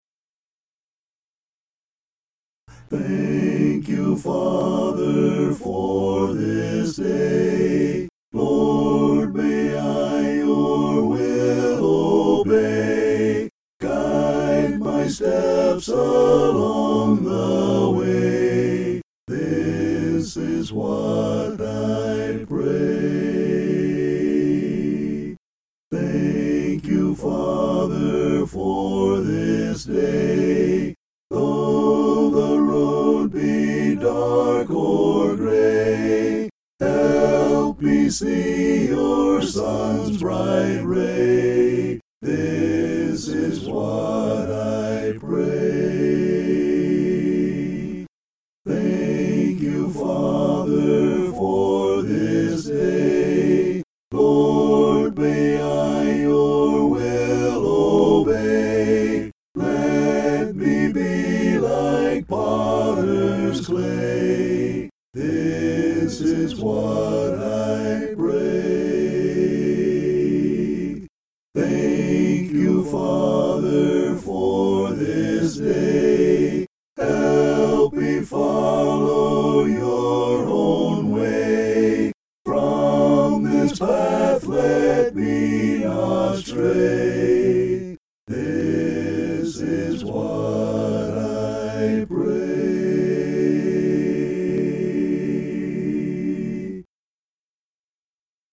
(An original hymn)